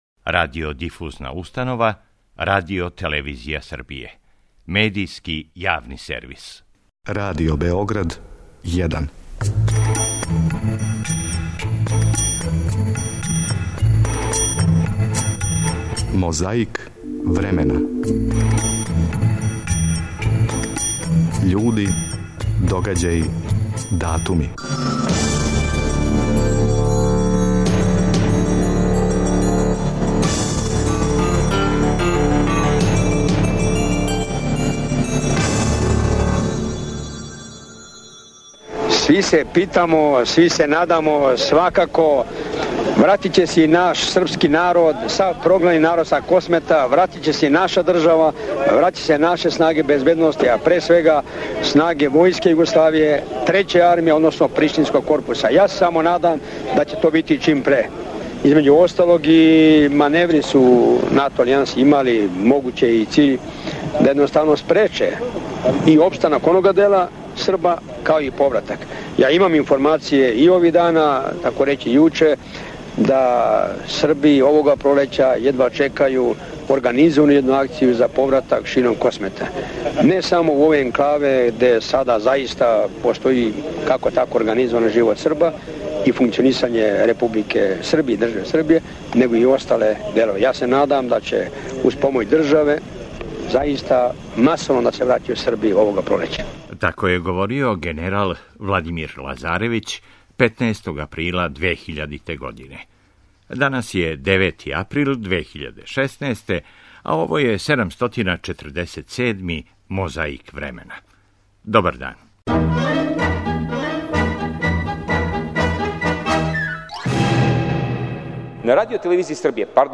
Место: Нишка бања.